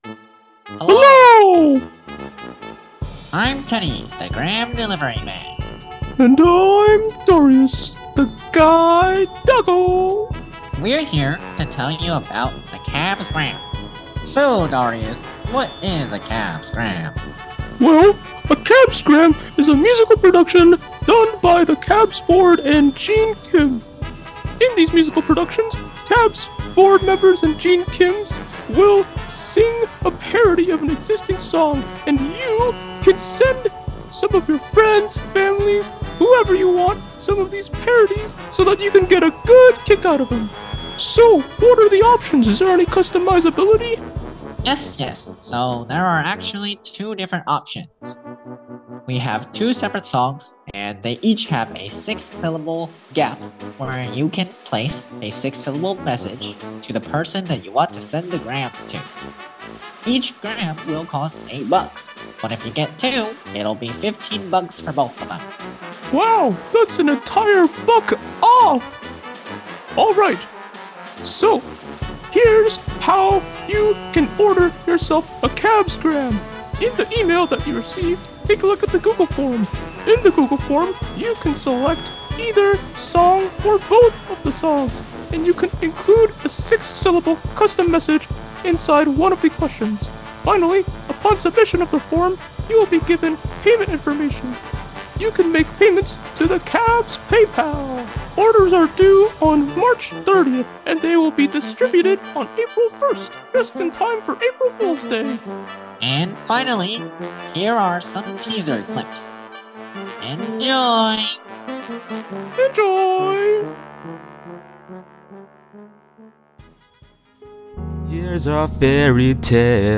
smile-inducing parodies